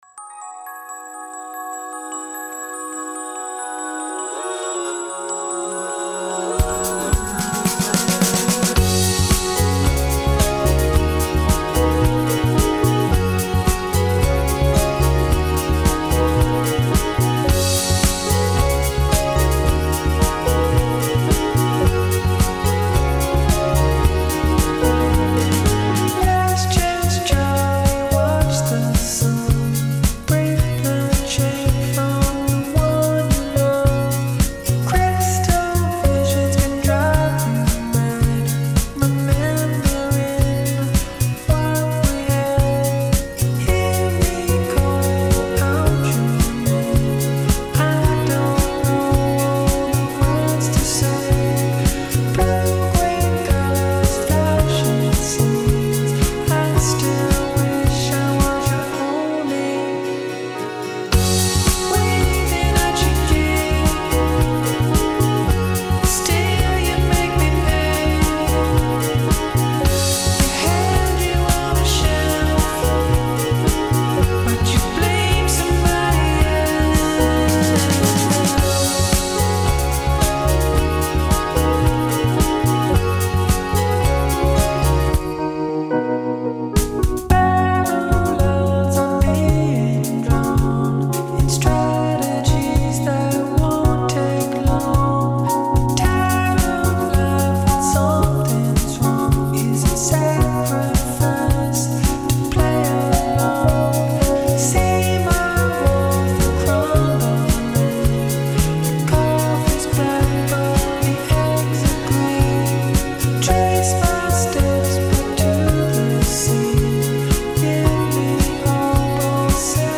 glistening synth pop w/ a summery groove
A glistening synth pop delight with a rock-solid core